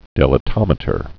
(dĭlə-tŏmĭ-tər, dīlə-)